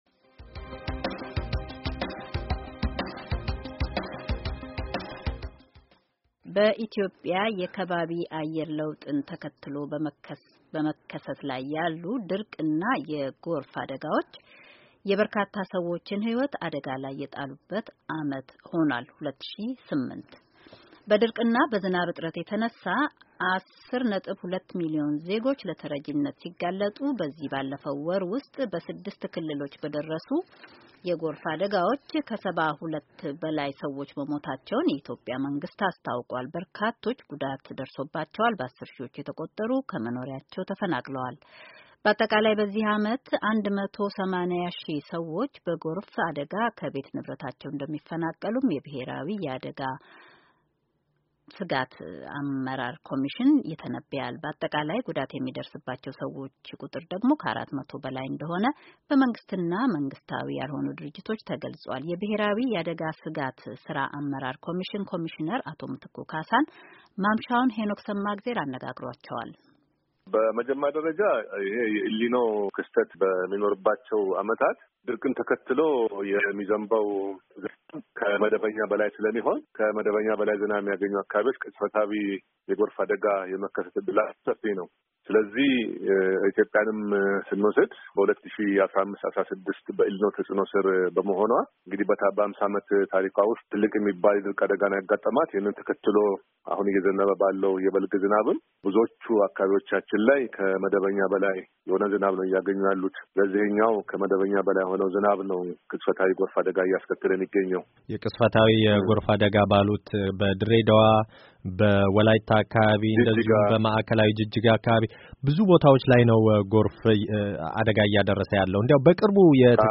ቃለ-ምልልስ ያጠቃለለ ዘገባ